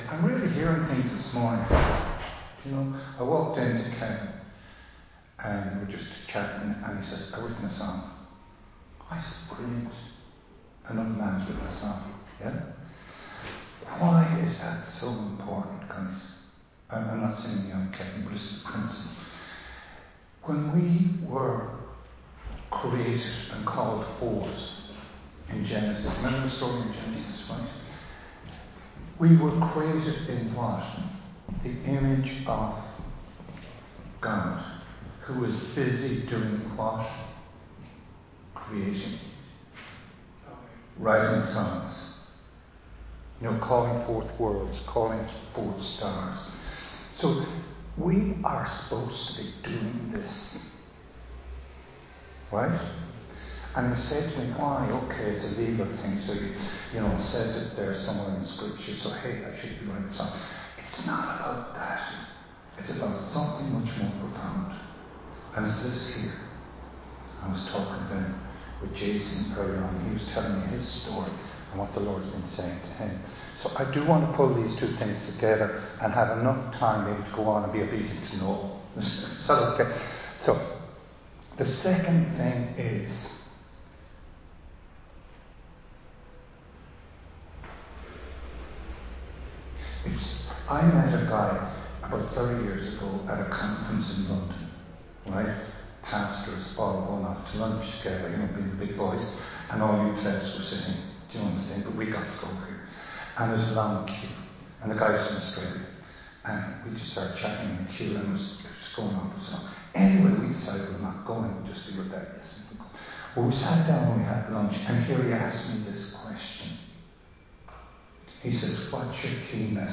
Recorded live in Liberty Church on 17 August 2025